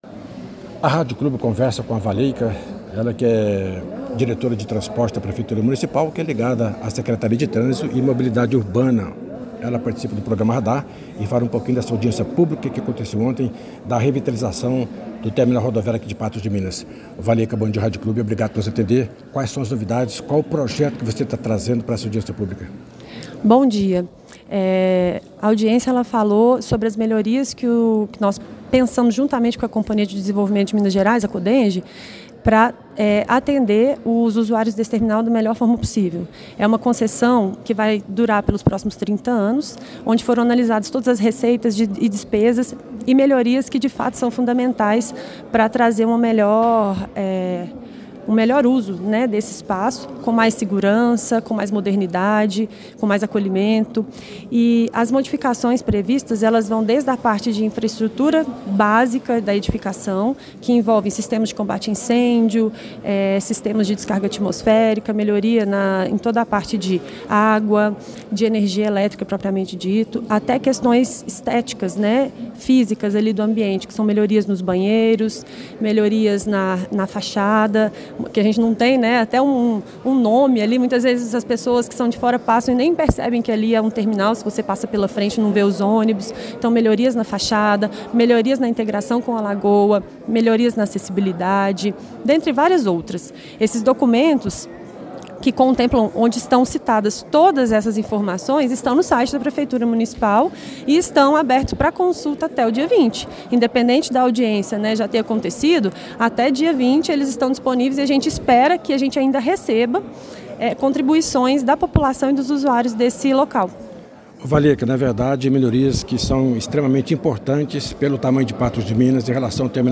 Ouça a entrevista completa sobre a audiência: 01